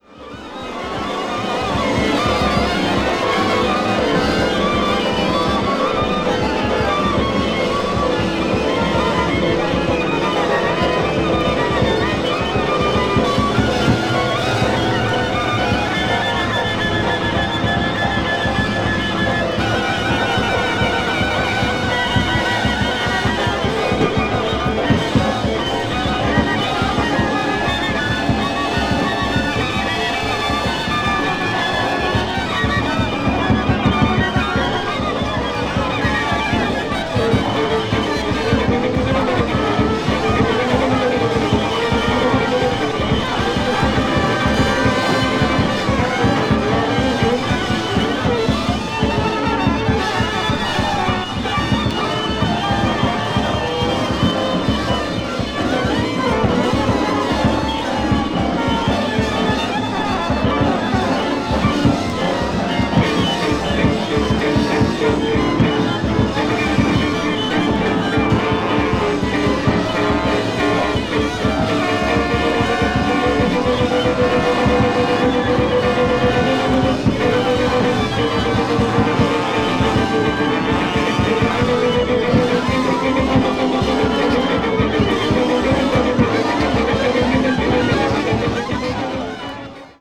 強烈。
avant-jazz   free improvisation   free jazz   jazz orchestra